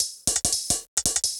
UHH_ElectroHatD_170-05.wav